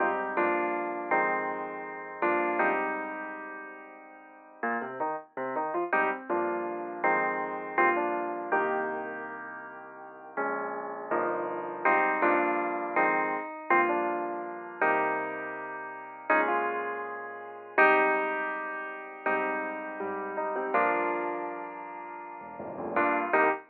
11 piano C.wav